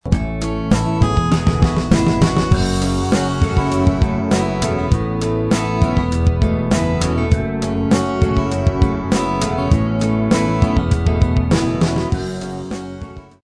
The sound wasn't at all what I originally intended; I had envisioned it as an upbeat electronic dance track, but instead it settled into a laid-back groove which I think suits it much better.
The lead melodies on bass and oboe were improvised on the spot.